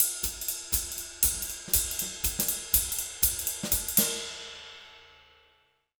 240SWING04-L.wav